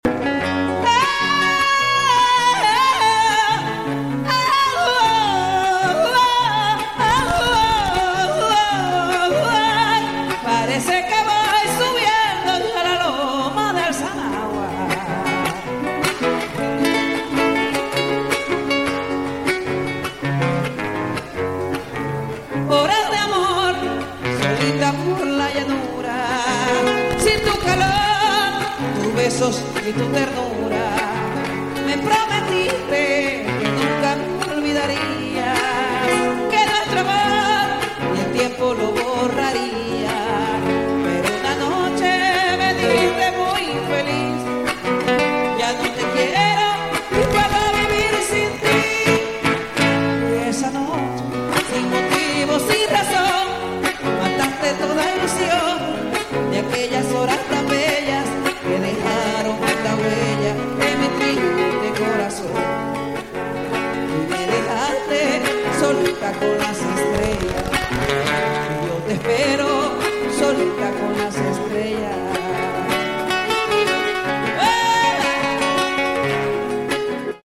es un gran honor disfrutar de su melodiosa voz